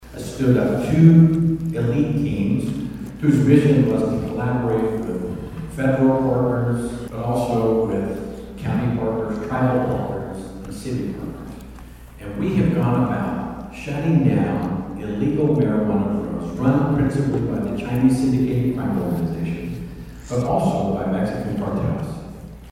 State AG Drummond Speaks to Bartlesville Rotary Club
Speaking in Bartlesville on Monday, Drummond told Rotary Club members that Oklahoma once had more than 12,000 licensed grow facilities, far exceeding what the market required—and feeding the black market.